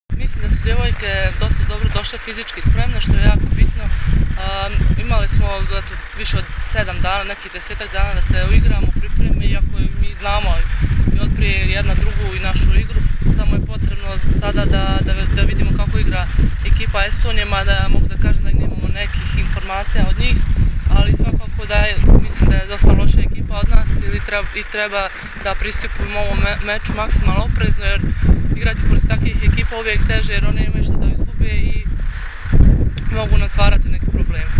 IZJAVA BRANKICE MIHJALOVIĆ